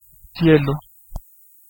Ääntäminen
IPA: [sjɛl]